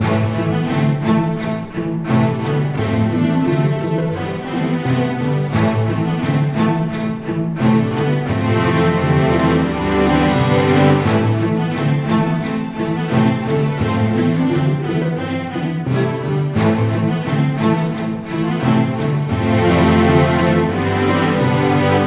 Synth-MarchToWar.wav